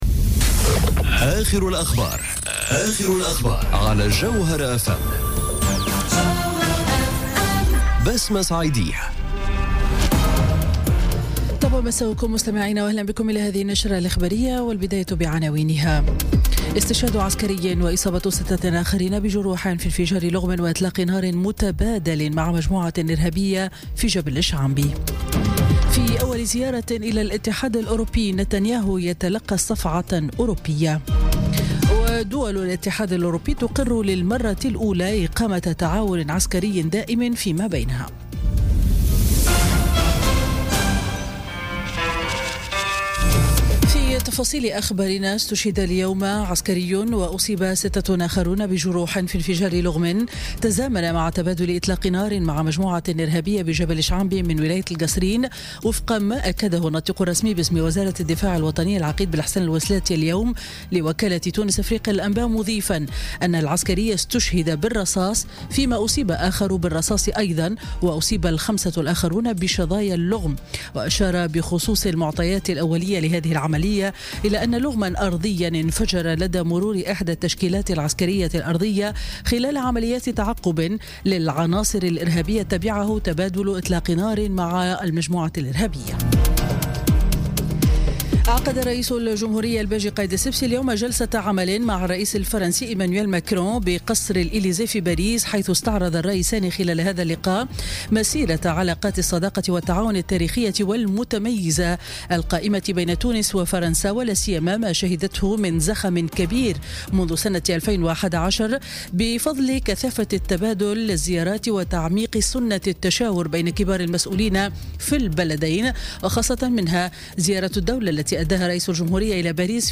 نشرة أخبار السابعة مساء ليوم الاثنين 11 ديسمبر 2017